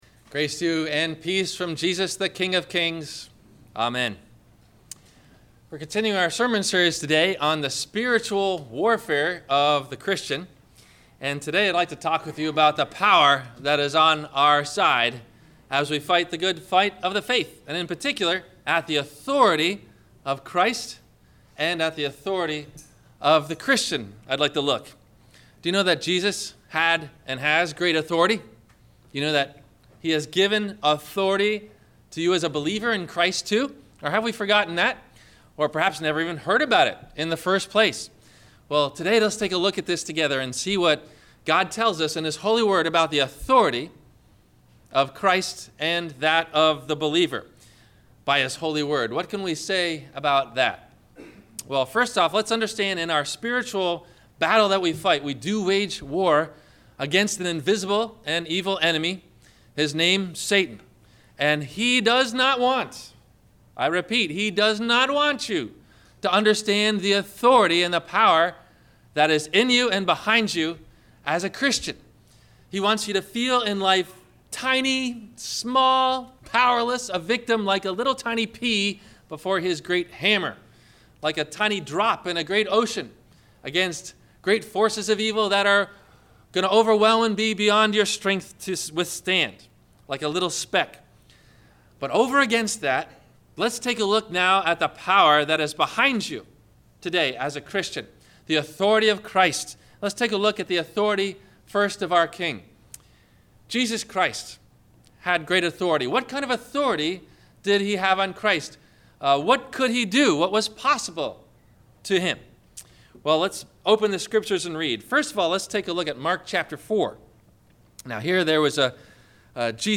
How Much Authority Does the Christian Have? – Sermon – March 15 2015